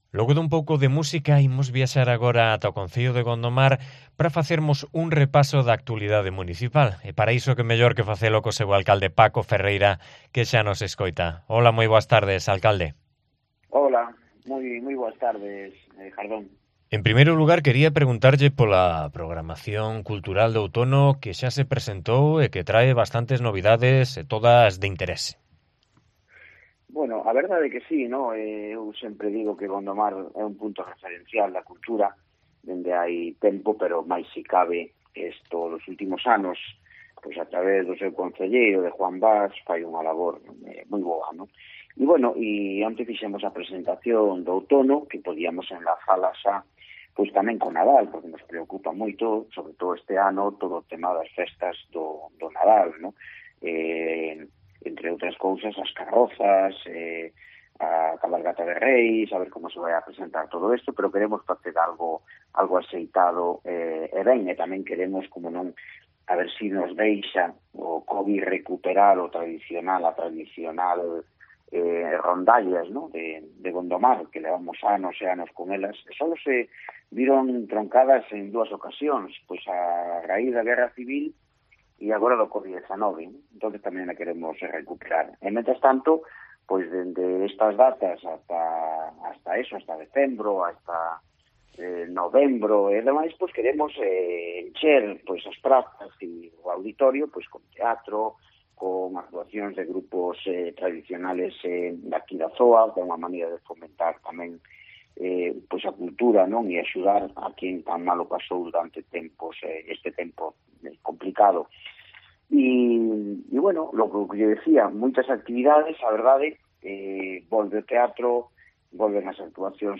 Entrevista a Paco Ferreira, alcalde de Gondomar